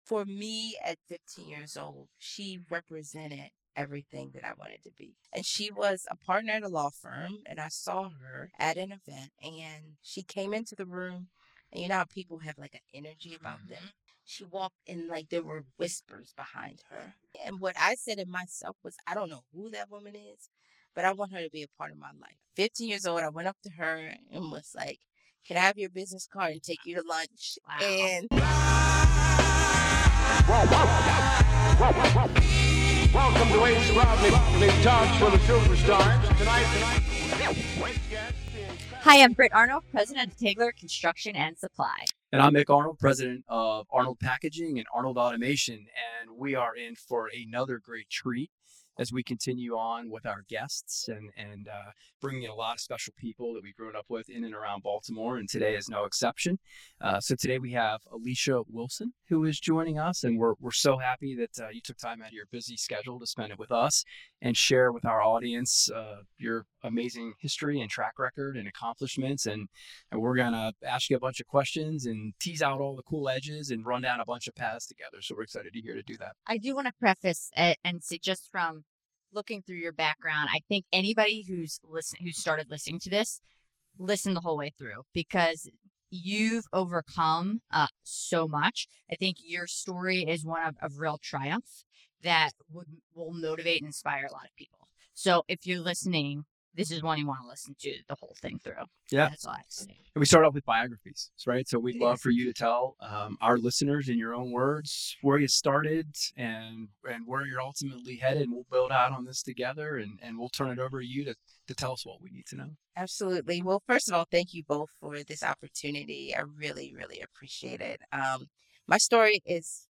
Subscribe for more powerful conversations on leadership, purpose, and parenthood.